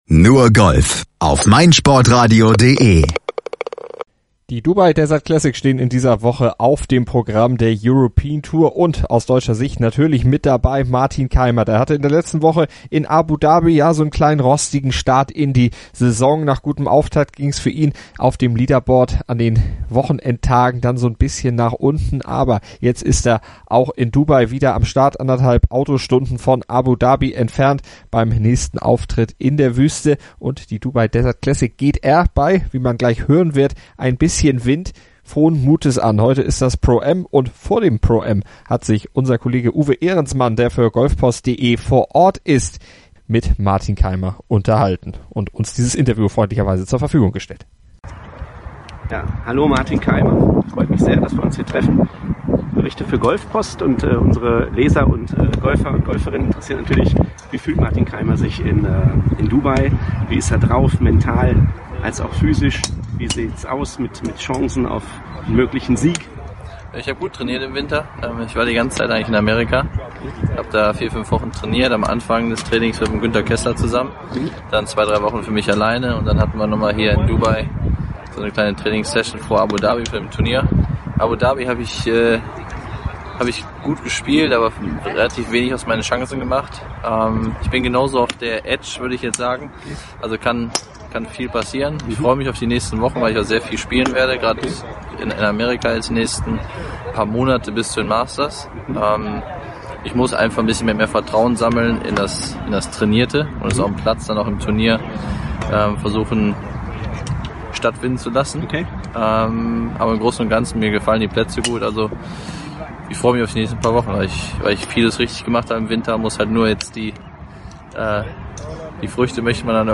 Interview: Kaymer vor Dubai-Start